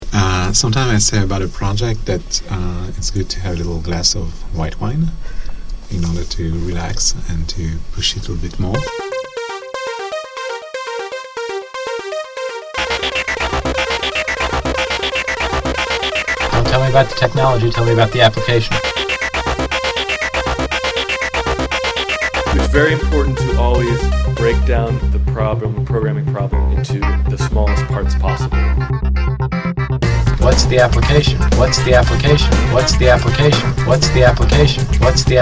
We were then going to overlay it with various faculty statements. After recording a bountiful cacophony of ITP effects, like the mirrors, lockers, drums and so forth, we focused in on the faculty voices. The effects made an okay beat, but GarageBand quickly proved the better tool to create a funky techno groove that mixed in compellingly with the voices.